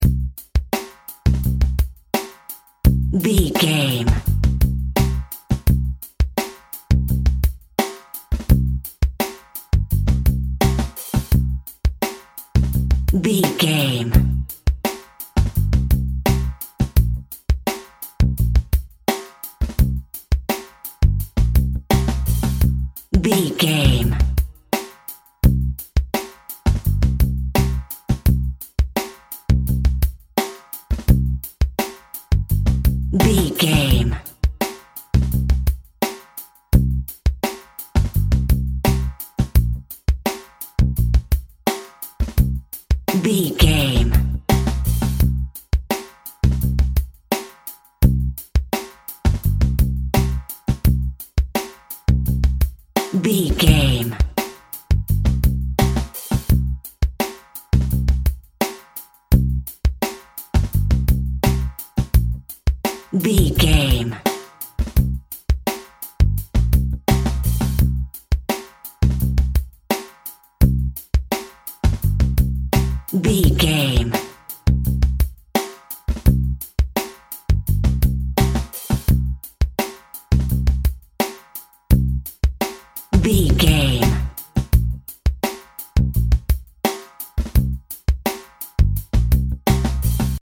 Creeping Music.
In-crescendo
Thriller
Aeolian/Minor
Slow
scary
tension
ominous
eerie
Drum and bass
break beat
electronic
sub bass
synth drums
synth leads
synth bass